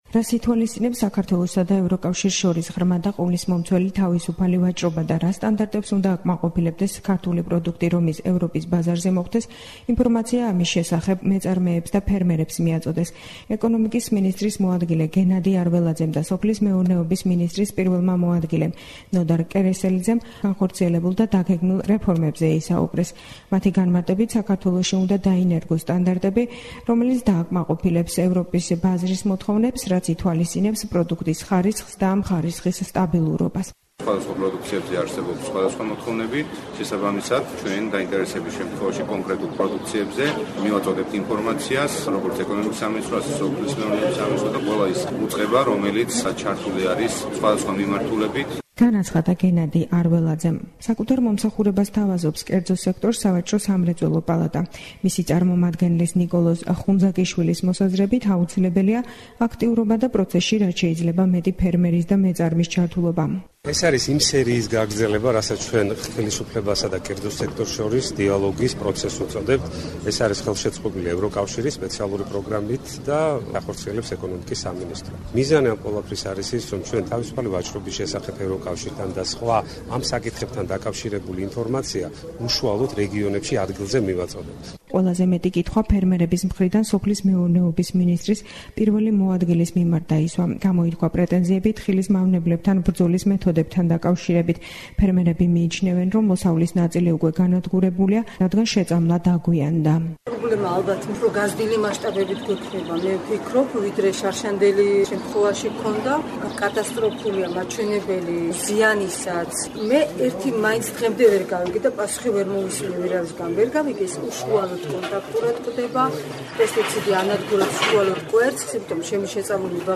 შეხვედრა ფერმერებთან
რას გულისხმობს საქართველოსა და ევროკავშირს შორის ღრმა და ყოვლისმომცველი თავისუფალი ვაჭრობა (DCFTA) და რა სტანდარტებს უნდა აკმაყოფილებდეს ქართული პროდუქტი, რომ ის ევროპის ბაზარზე მოხვდეს, მეგრელ მეწარმეებსა და ფერმერებს ამის შესახებ ინფორმაცია მიაწოდეს. შეხვედრას, რომელიც ზუგდიდის ტექნოპარკში გაიმართა, ორგანიზაცია გაუწია საქართველოს ეკონომიკისა და მდგრადი განვითარების სამინისტრომ და ევროპულმა პროექტმა „ასოცირების ხელშეკრულების განხორციელების ხელშეწყობა“.
მრგვალი მაგიდის ფორმატის საინფორმაციო შეხვედრაზე ეკონომიკის მინისტრის მოადგილემ გენადი არველაძემ და სოფლის მეურნეობის მინისტრის პირველმა მოადგილემ ნოდარ კერესელიძემ DCFTA-ს ფარგლებში განხორციელებულ და დაგეგმილ რეფორმებზე ისაუბრეს. მათი განმარტებით, საქართველოში უნდა დაინერგოს ევროპის ბაზრის მოთხოვნების შესაბამისი სტანდარტები, რაც პროდუქტის ხარისხსა და ამ ხარისხის სტაბილურობას ითვალისწინებს.